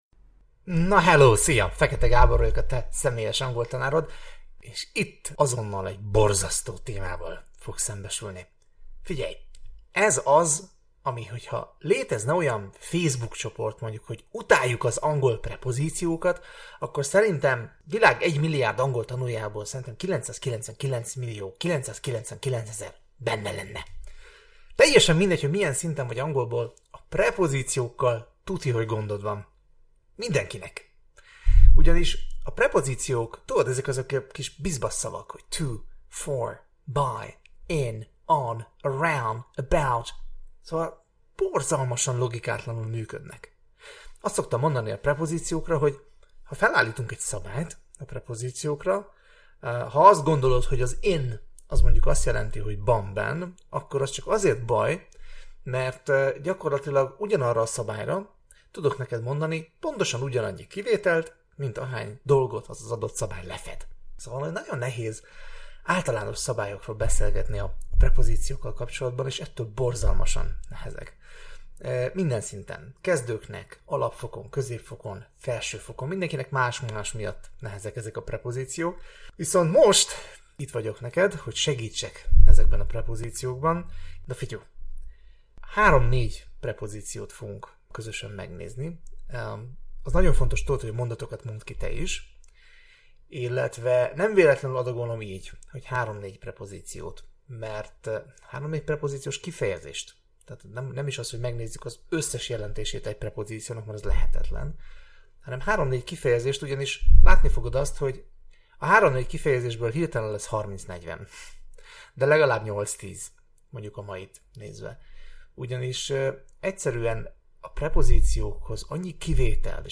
Tanári magyarázat
tanarimagyarazat.mp3